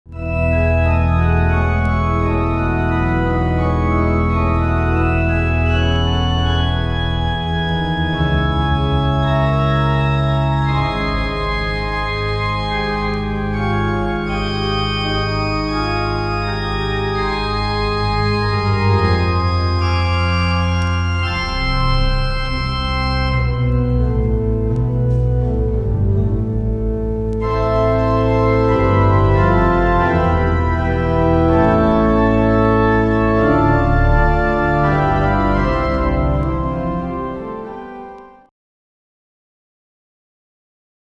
Värmdö kyrka 1977.